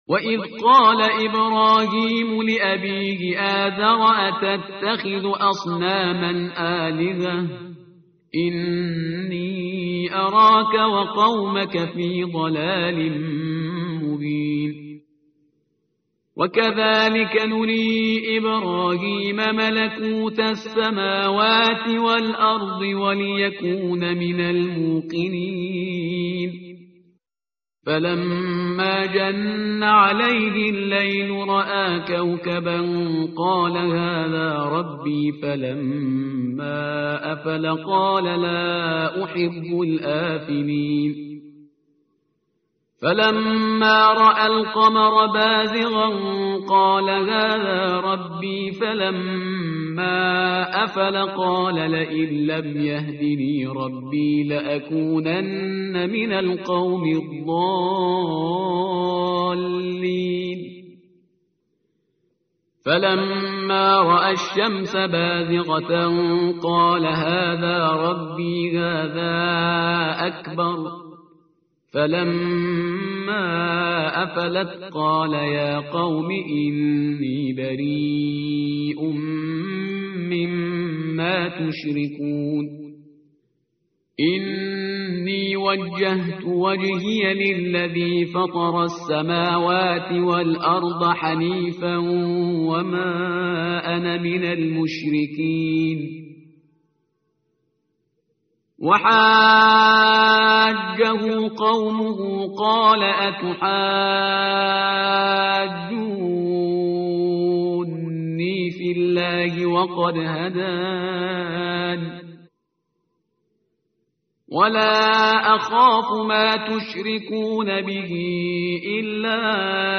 متن قرآن همراه باتلاوت قرآن و ترجمه
tartil_parhizgar_page_137.mp3